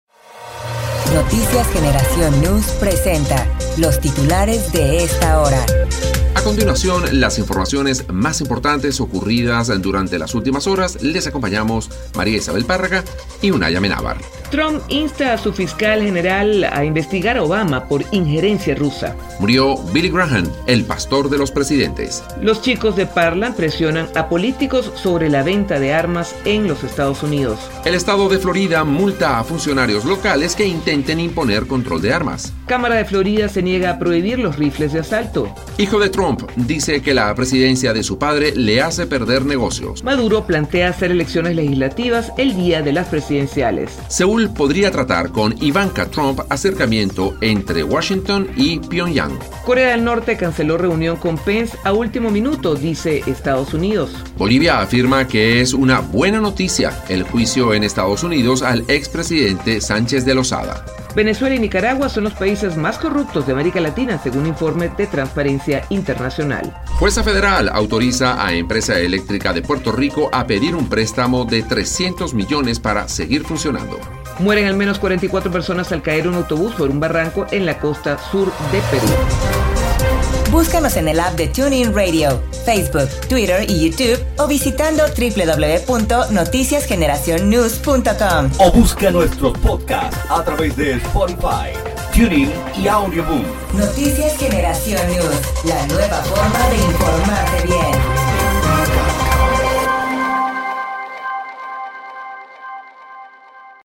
Titulares de Noticias